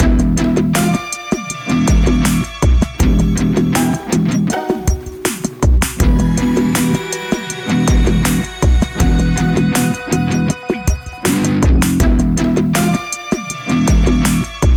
Source Ripped from the game
trimmed to 29.5 seconds and faded out the last two seconds